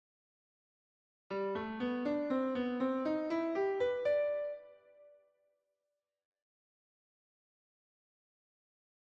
Improvisation Piano Jazz
Enclosure et approche chromatique
Un chromatisme consiste à approcher une note ( target ) de l’accord ou de la gamme via un ou plusieurs demi-tons ( n’appartenant éventuellement pas à la gamme )